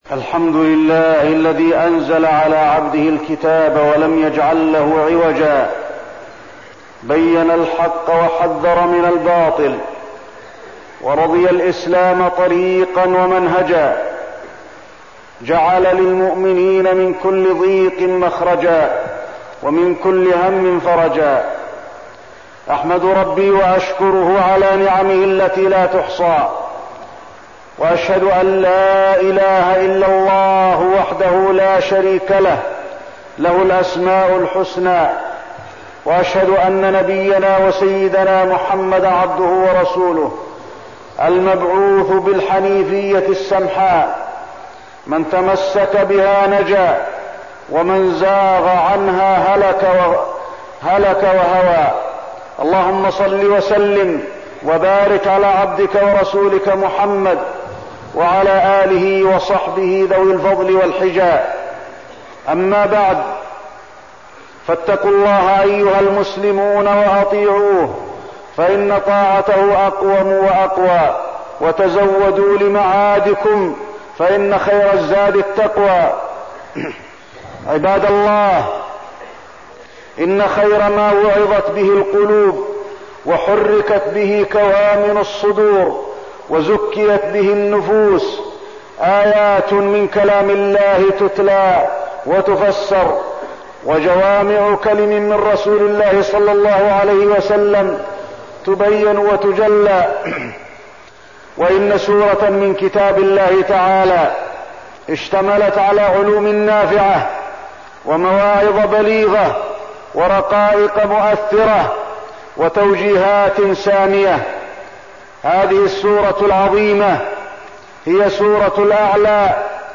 تاريخ النشر ٤ ربيع الثاني ١٤١٥ هـ المكان: المسجد النبوي الشيخ: فضيلة الشيخ د. علي بن عبدالرحمن الحذيفي فضيلة الشيخ د. علي بن عبدالرحمن الحذيفي تفسير سورة الأعلى